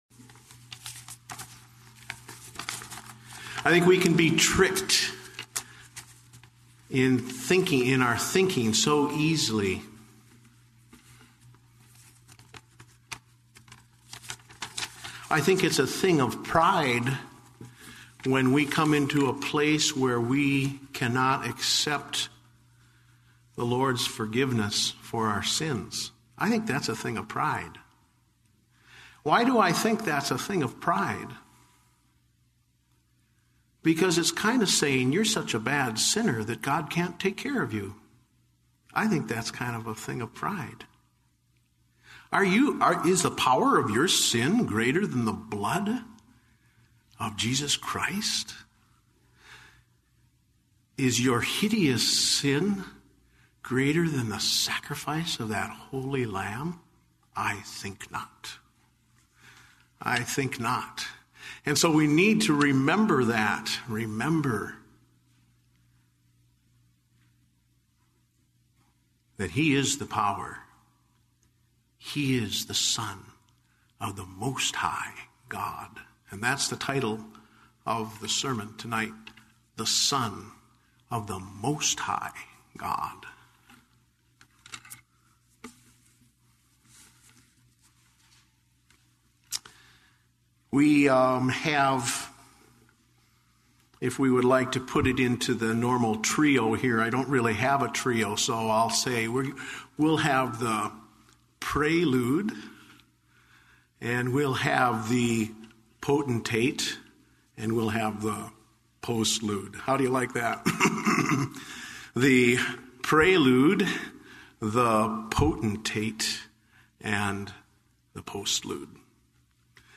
Date: March 1, 2009 (Evening Service)